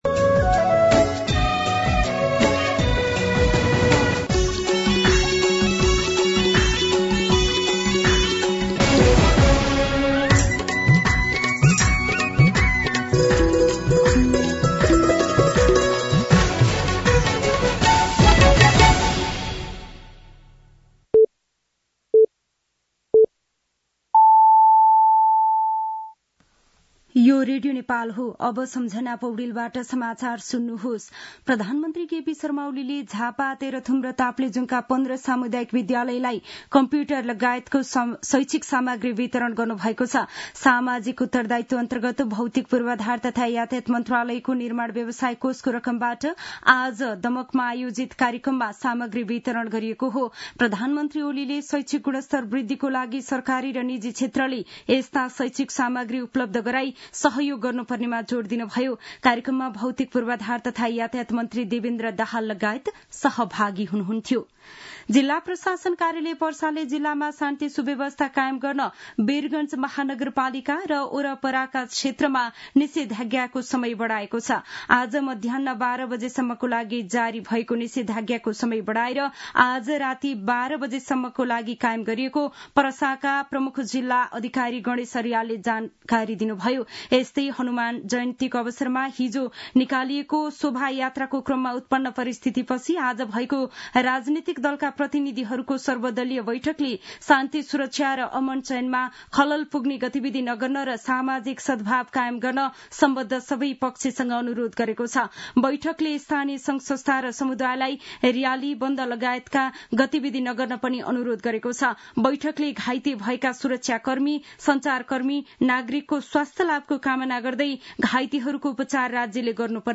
दिउँसो १ बजेको नेपाली समाचार : ३१ चैत , २०८१
1-pm-Nepali-News-4.mp3